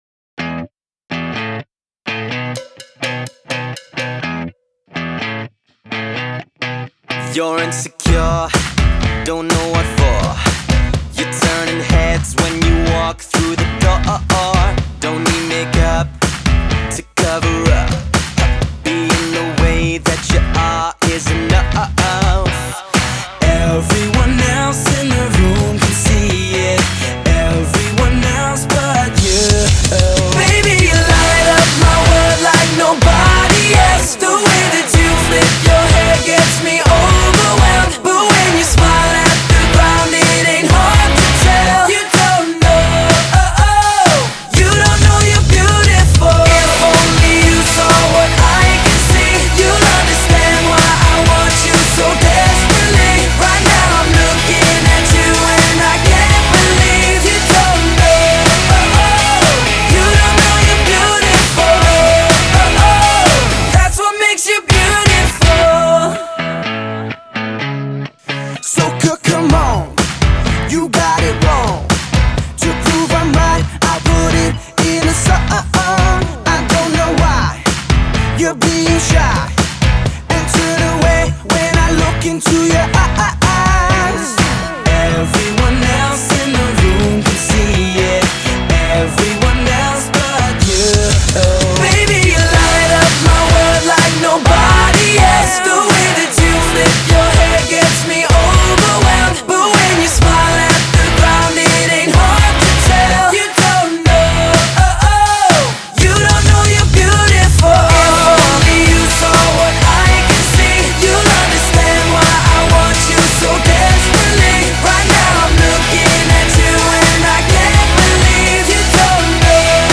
англо-ирландский бой-бэнд